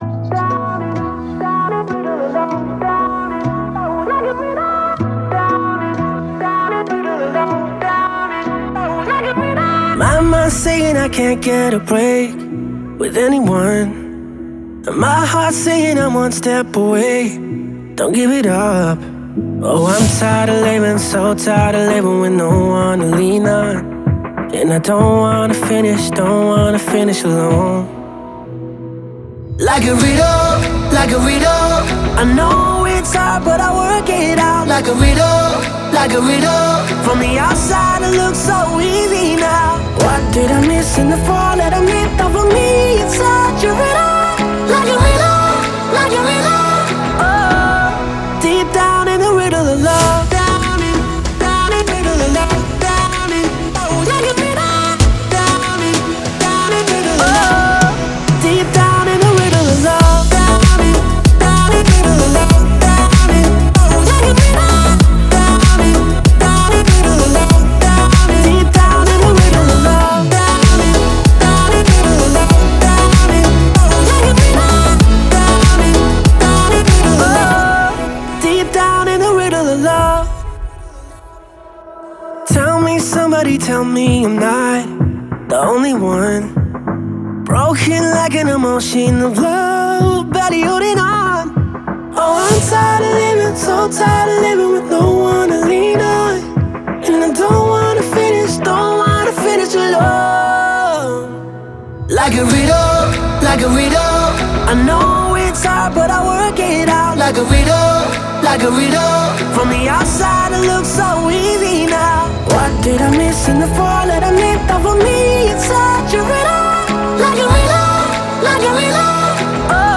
это яркий трек в жанре deep house